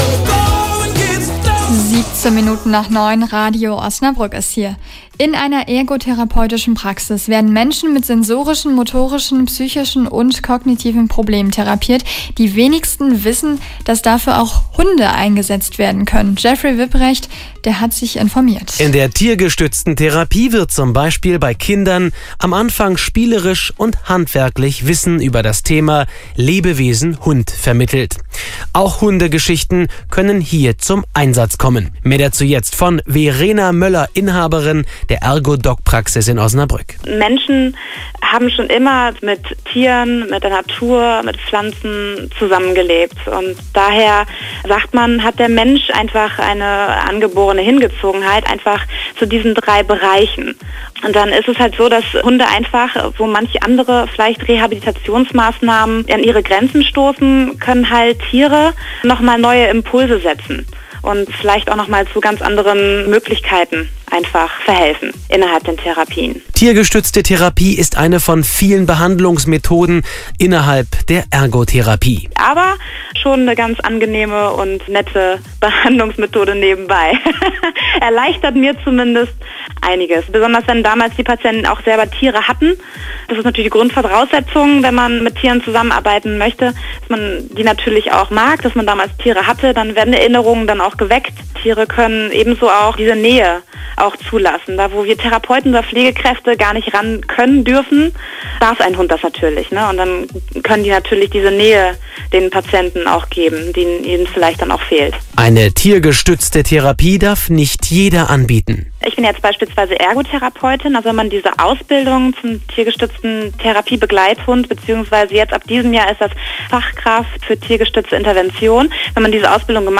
Am Mittwoch, den 13.02.2014,  gab ErgoDog sein allererstes Interview zum Thema Einsatz von Hunden innerhalb der Therapie.
Aufmerksam wurde Osnabrück Radio (98,2) über diese Homepage und rief prombt zwei Tage zuvor an.